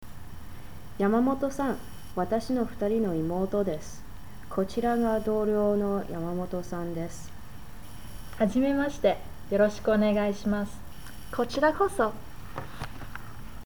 Japanese Conversation Set #8